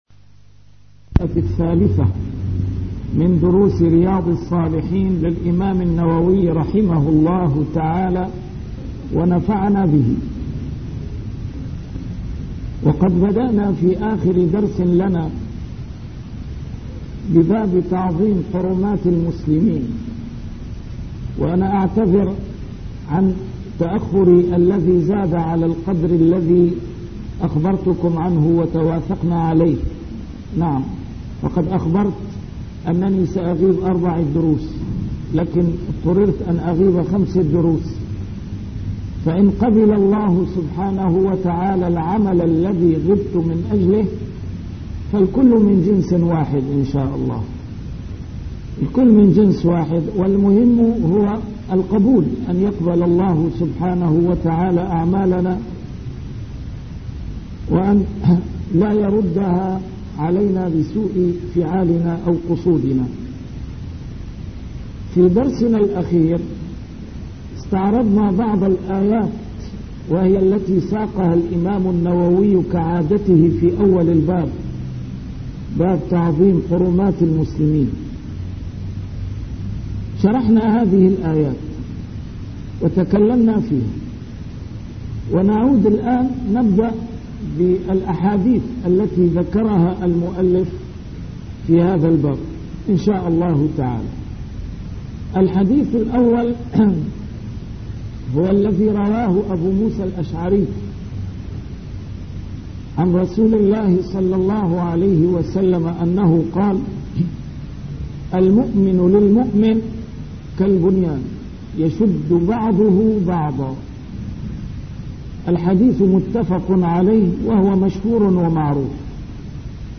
A MARTYR SCHOLAR: IMAM MUHAMMAD SAEED RAMADAN AL-BOUTI - الدروس العلمية - شرح كتاب رياض الصالحين - 326- شرح رياض الصالحين: تعظيم حرمات المسلمين